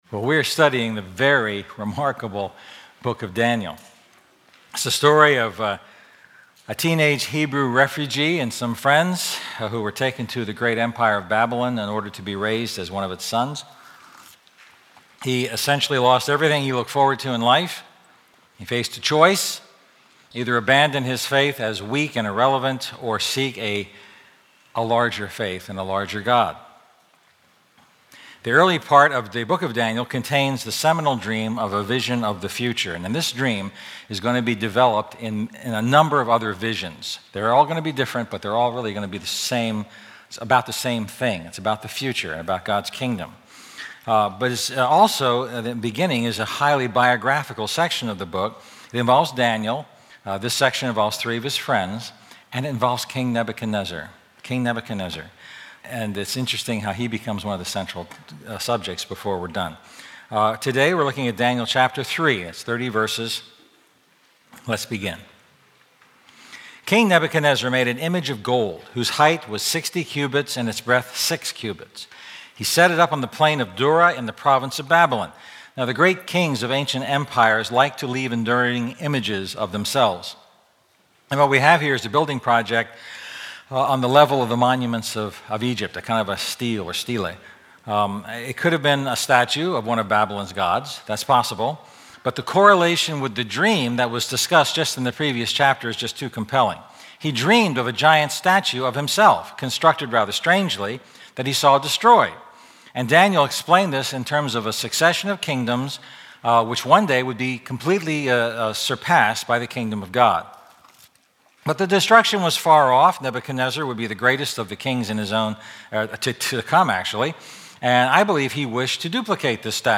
A message from the series "A Larger Faith."